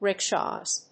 rickshaws.mp3